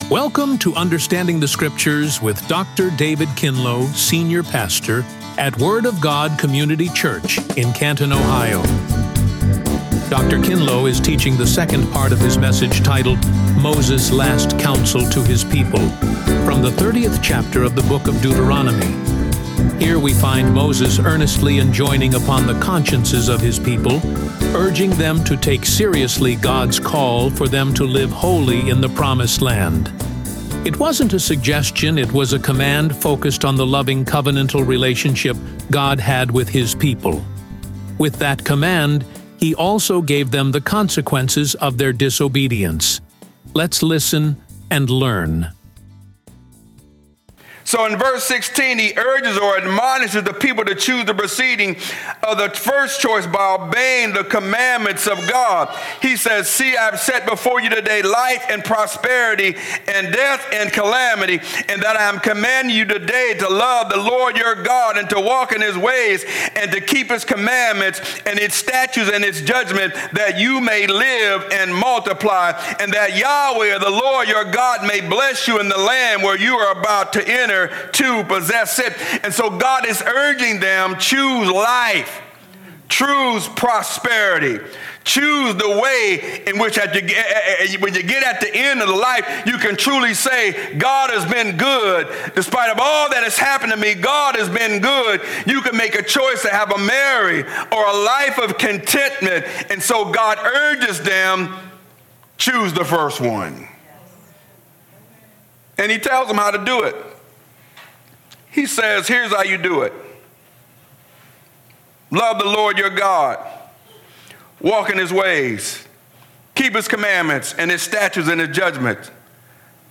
RADIO SERMON